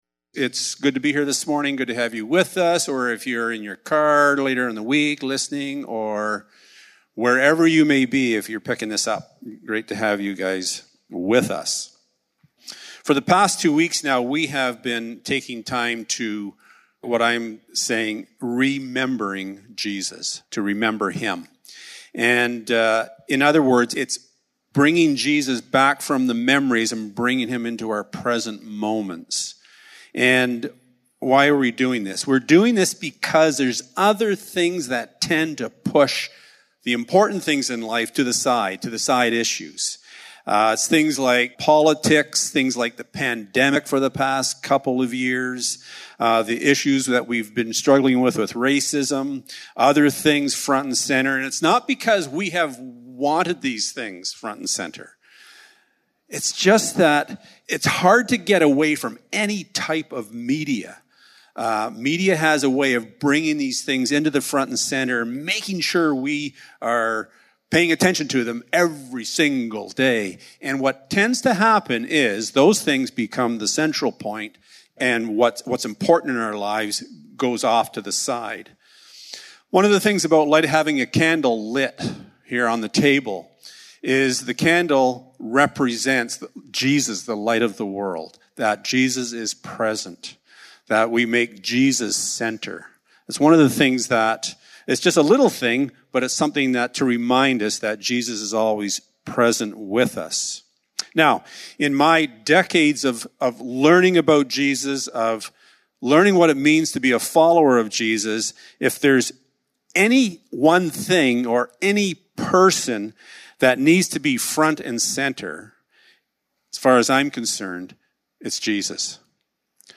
1 Service Type: Sunday Morning The Undercurrent of His Heart Who is this Jesus that we say we follow and worship?